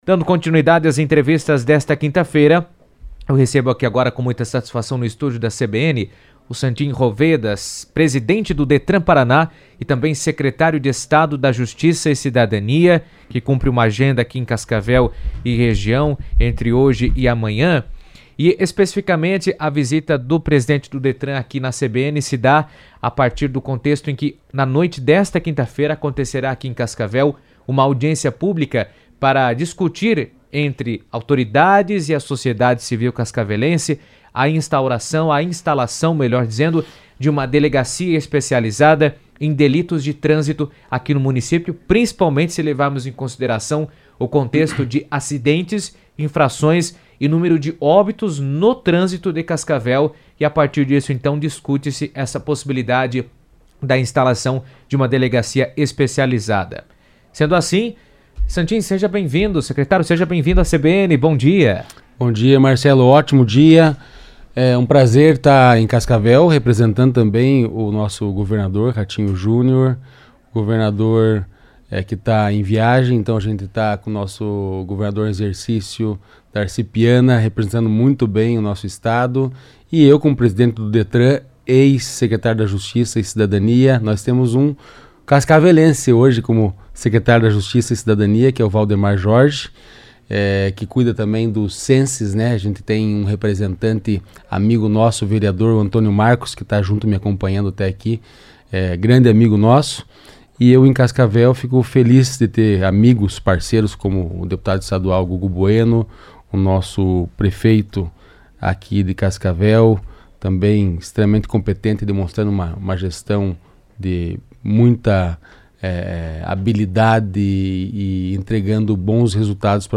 O presidente do Detran-PR, Santin Roveda, falou sobre o tema em entrevista à CBN.